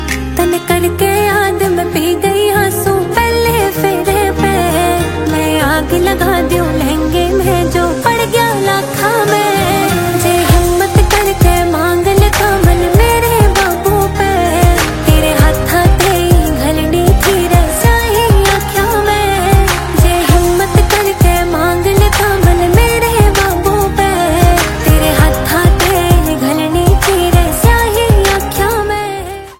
Ringtone File
emotional Haryanvi song